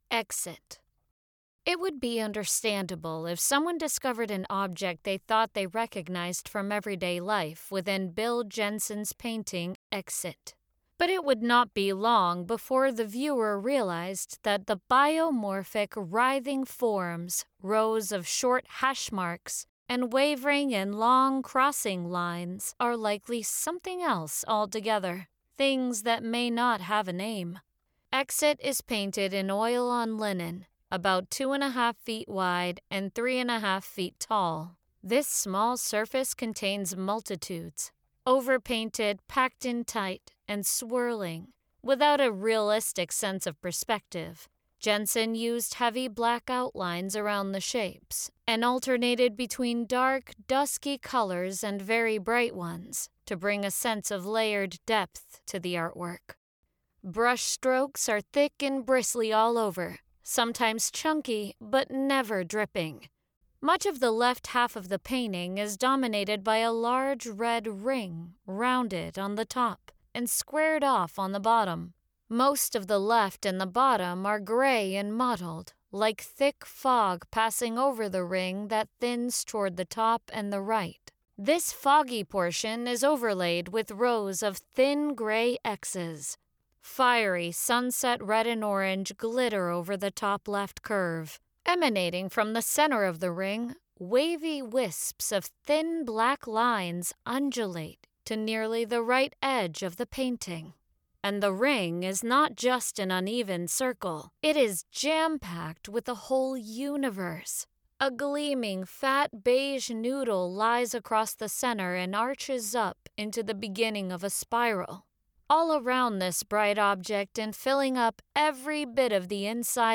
Audio Description (03:30)